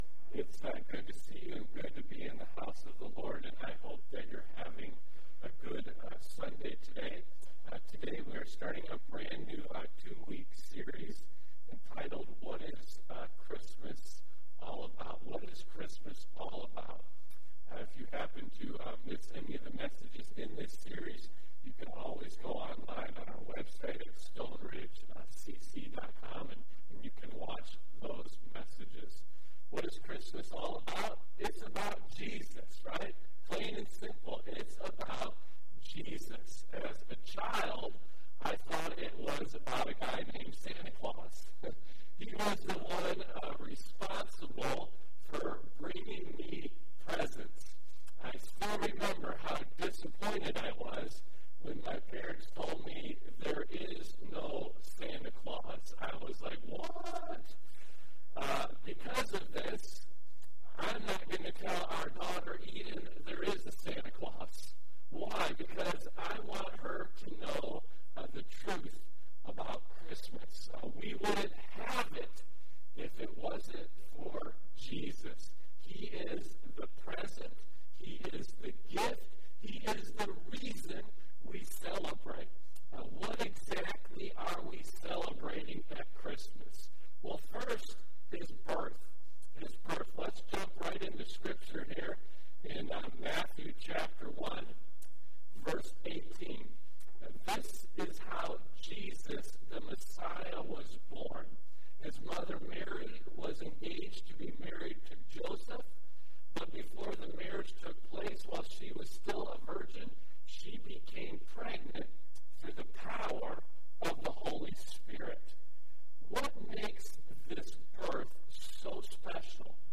Stone Ridge Community Church Sermon Audio Library